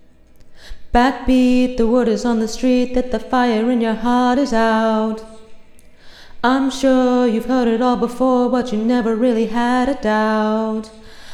Sung Mimic